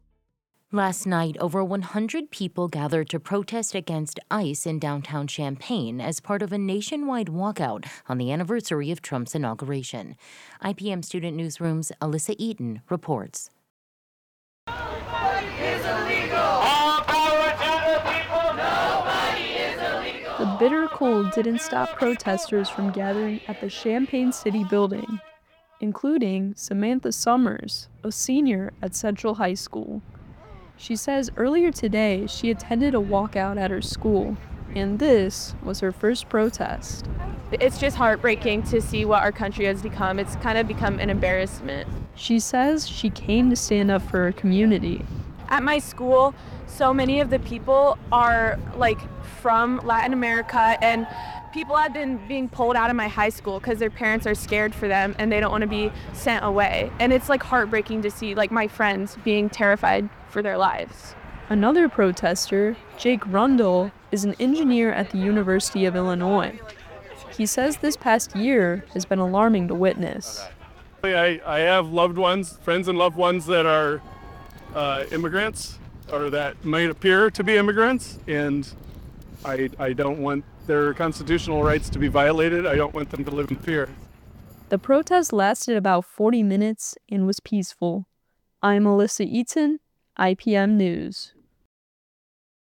shouts “All power” as protesters respond “To the people” during the demonstration.
ICEprotest.mp3